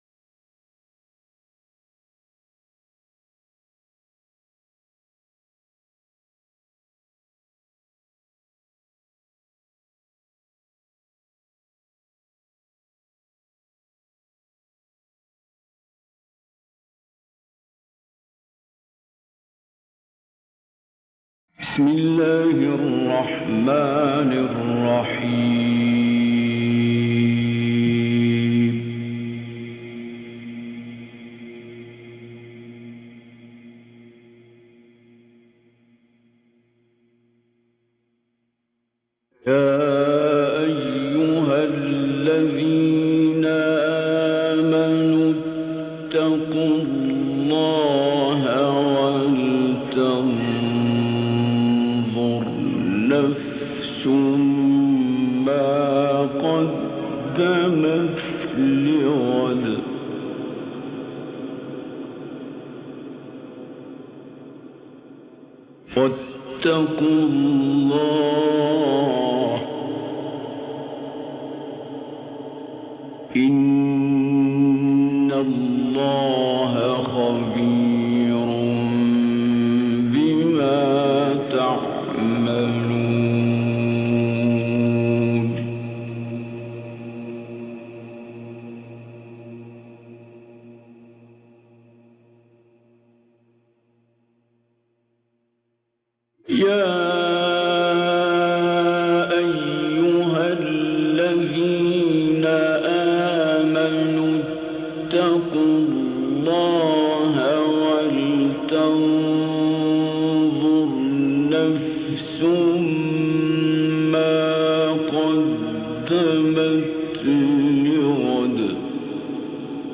حفلات نادرة جدا للشيخ عبد الباسط عبد الصمد النادرة فى الولايات المتحدة الأمريكية
حفل شيكاغو